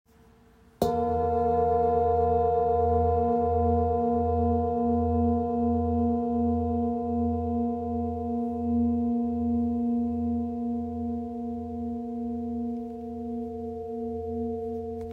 GSB Singing Bowl 23.5cm - 29.5cm
Light in weight yet remarkably strong in sound, this bowl produces deep, grounding vibrations that can be both felt and heard.